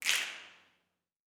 SNAPS 01.wav